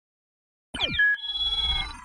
Crewmate Spawn - Onmiddellijk geluidseffect knop | Myinstants
crewmate-spawn.mp3